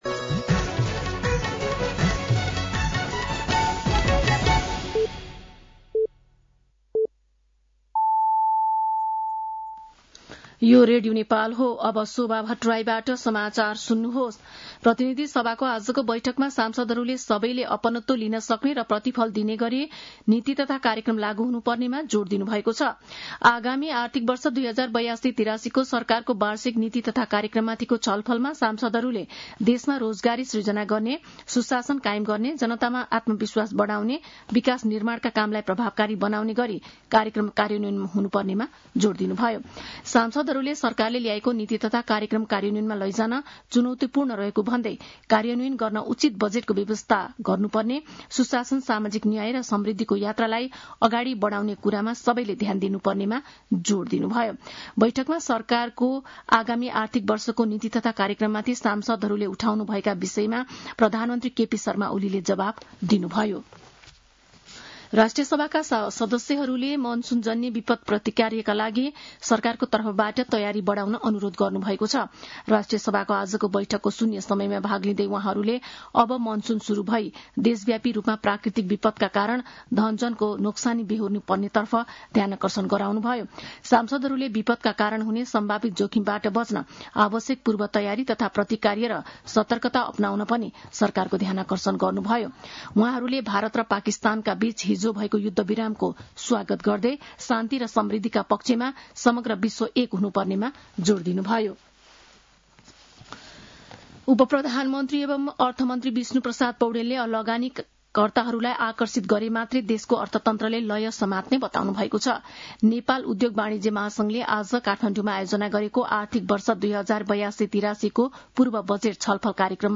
An online outlet of Nepal's national radio broadcaster
साँझ ५ बजेको नेपाली समाचार : २८ वैशाख , २०८२
5.-pm-nepali-news-1.mp3